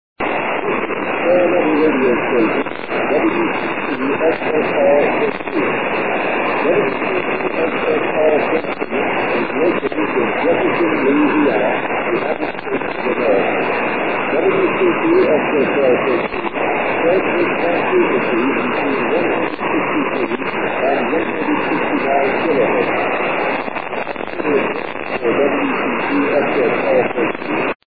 It is about three-quarters of the complete 42 second ID loop. Static punches a few holes in the signal, but it is readable.
WC2XSR/13 is transmitting on lower sideband at a carrier frequency of 168.0 KC with 400 watts PEP transmitter output.